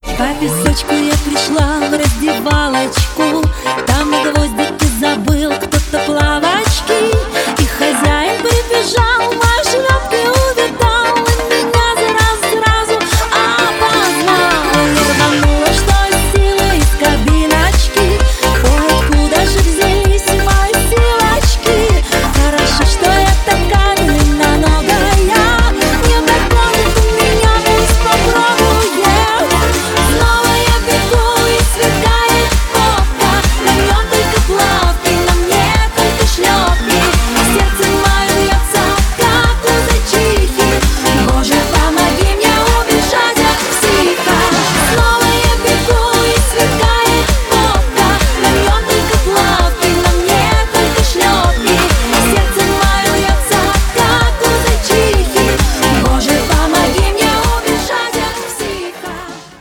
• Качество: 320, Stereo
веселые
dance